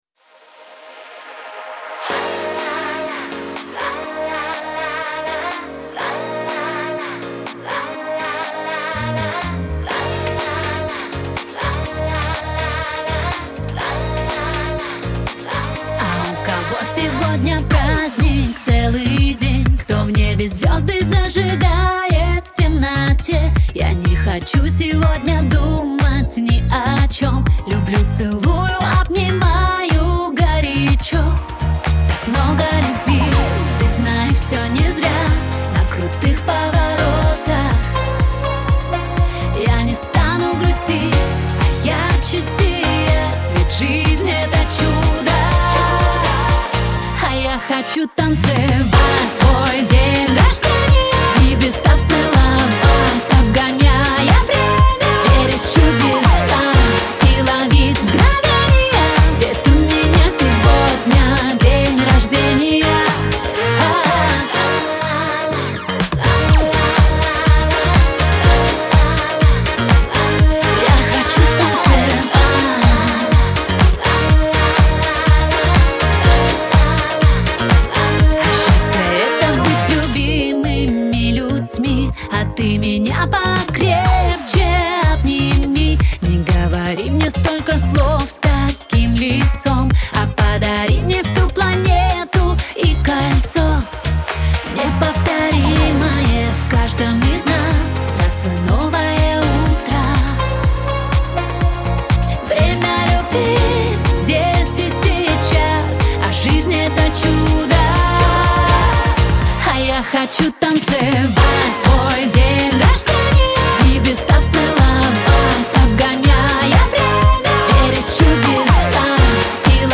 Веселая музыка